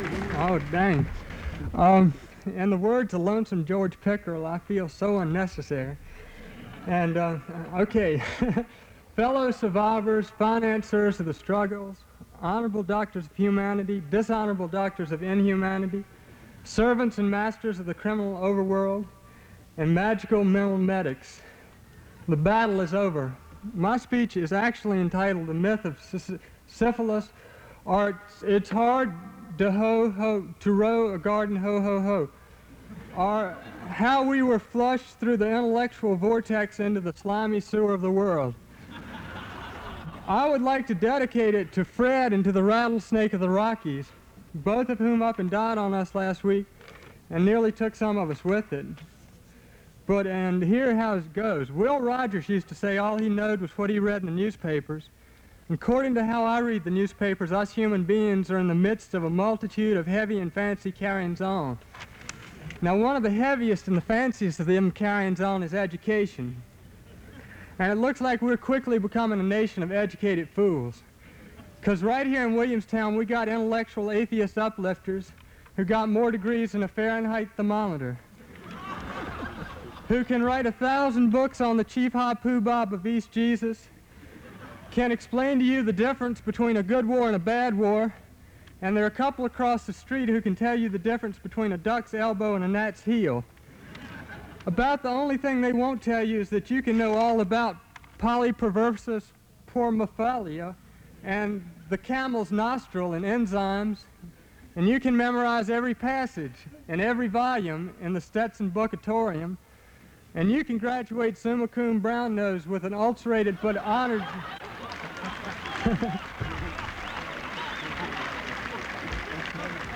Commencement Speech, June 6, 1971: